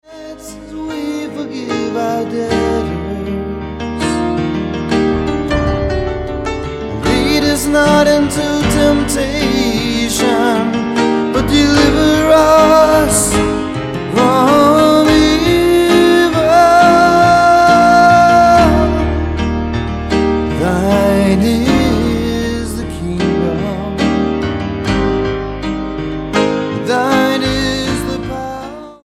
STYLE: Roots/Acoustic
Songwriting Demo